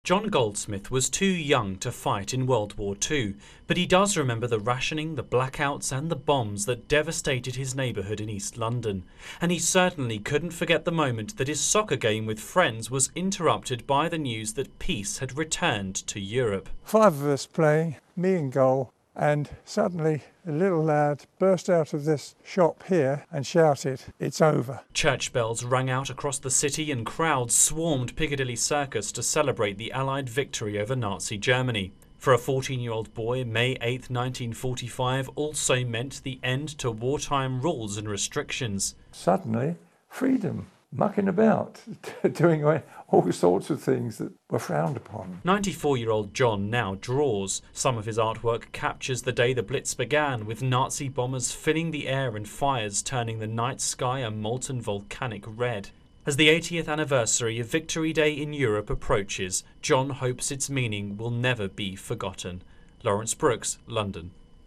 reports on an East End boy remembering the day World War II ended.